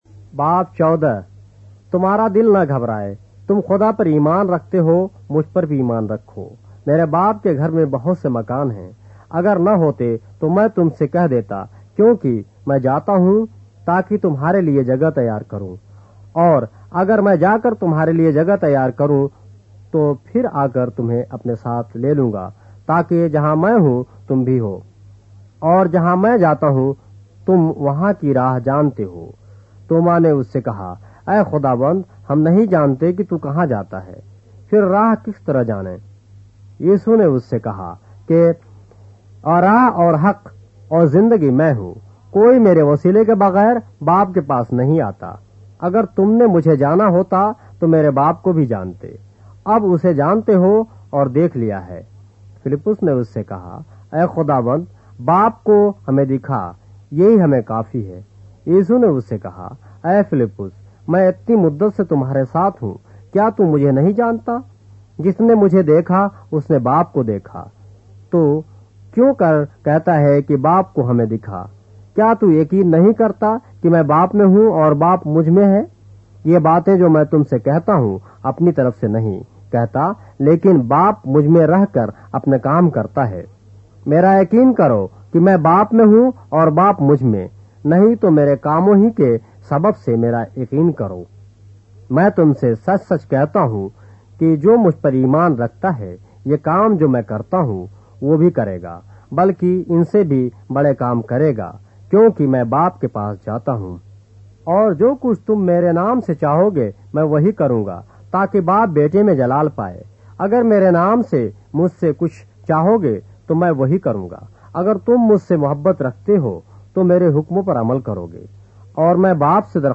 اردو بائبل کے باب - آڈیو روایت کے ساتھ - John, chapter 14 of the Holy Bible in Urdu